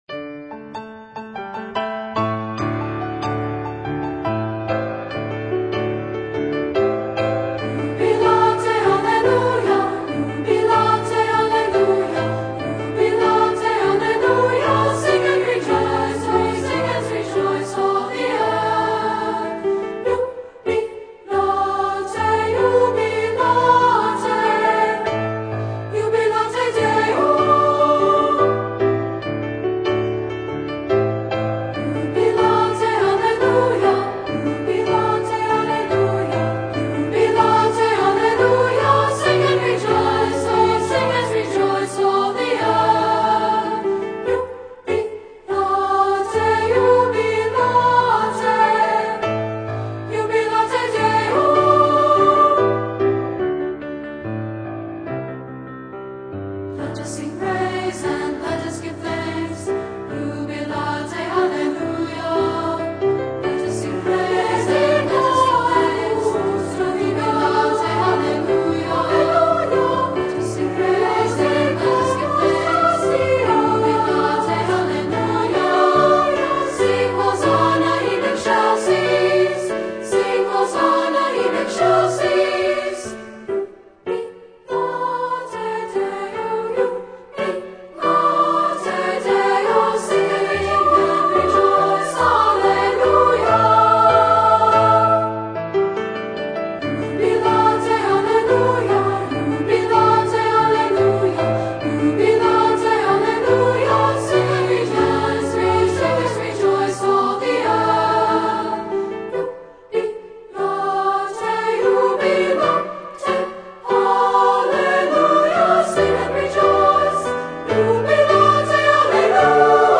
Voicing: 2-Part